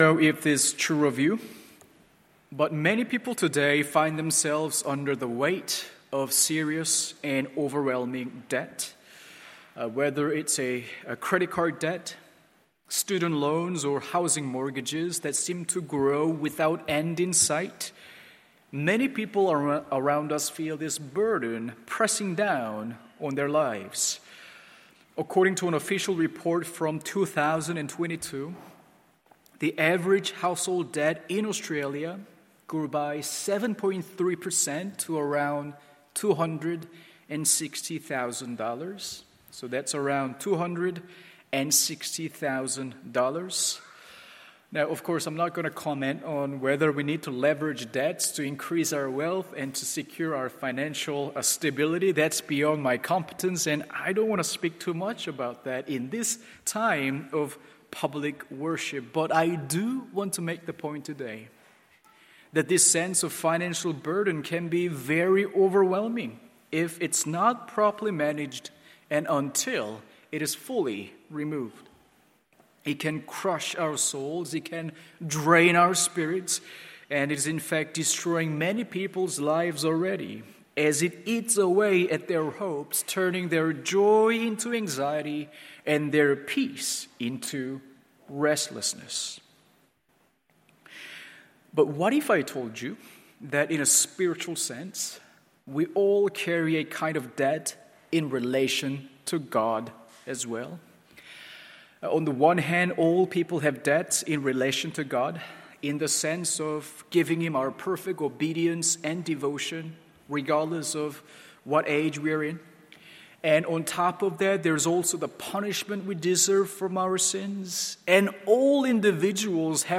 MORNING SERVICE Romans: 8:12-17…